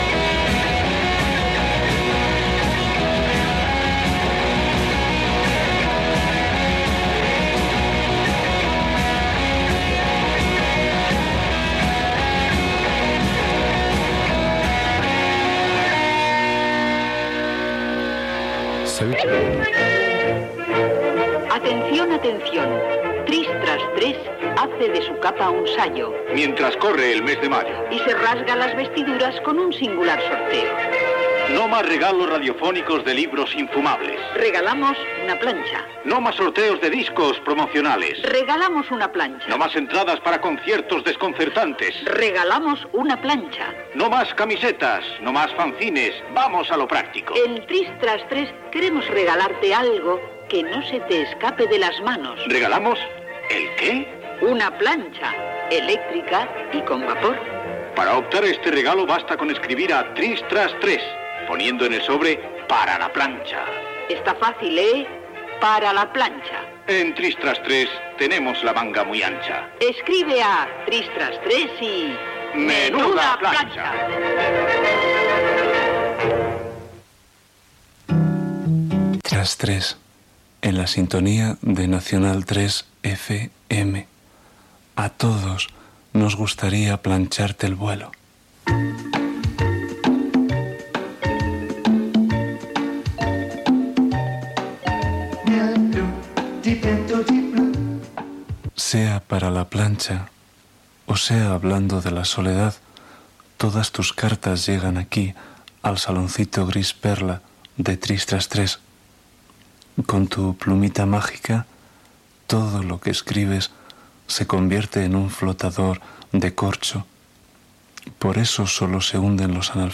Tema musical, concurs del programa per "regalar una planxa", identificació del programa, comentari, tema musical, un africà que no li cal una planxa per a la roba, tema musical
Entreteniment